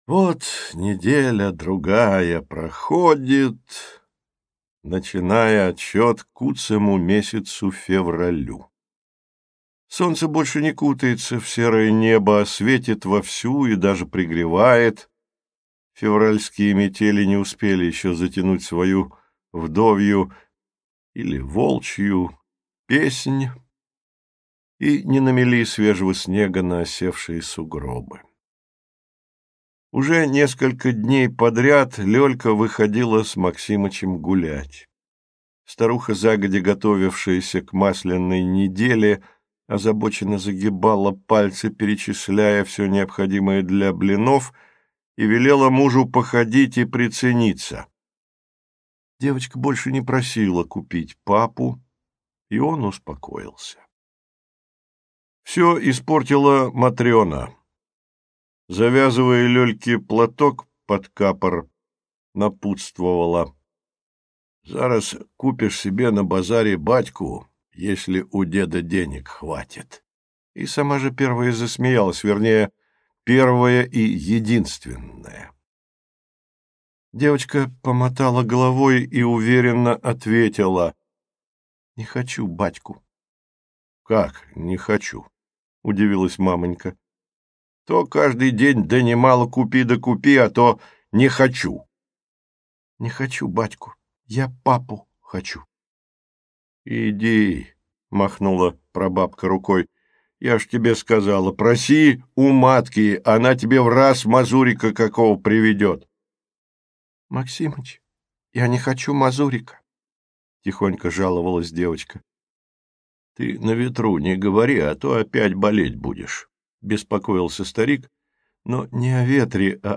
Аудиокнига Жили-были старик со старухой | Библиотека аудиокниг